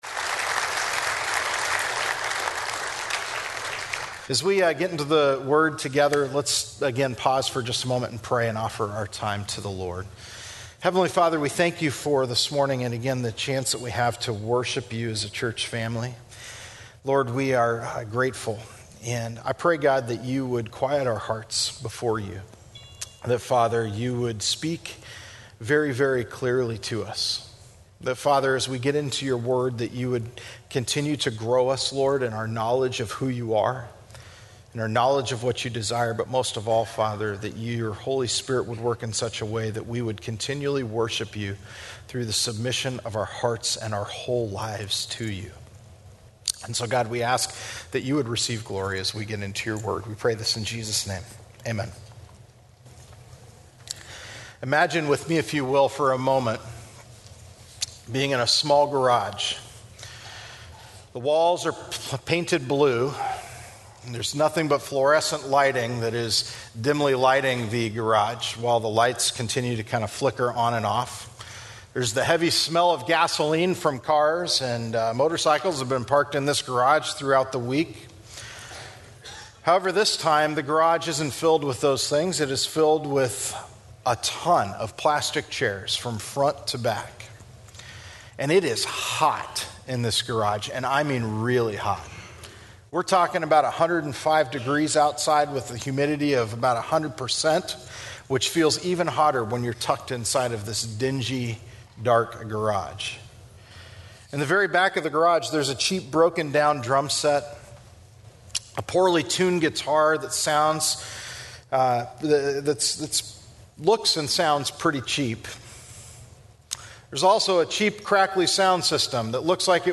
Westgate Chapel Sermons FAQ - Should Worship Style Matter?